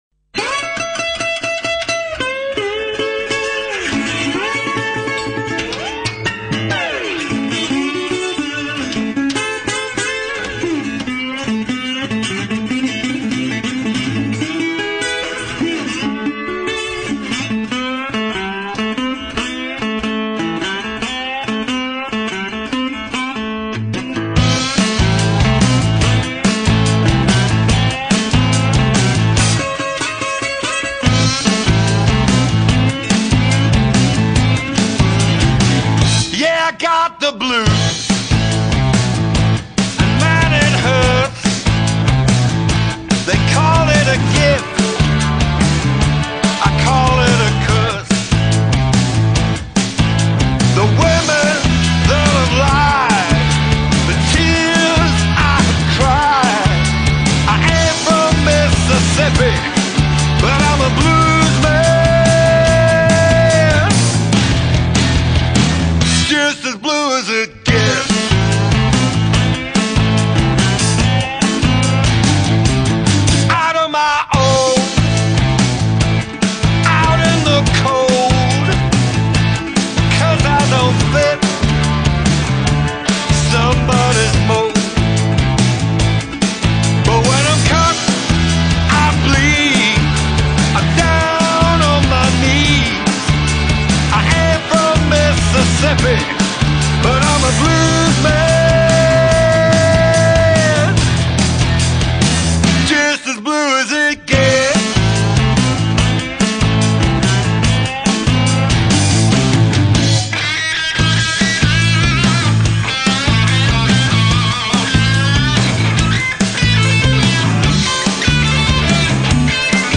блюз
рок-музыка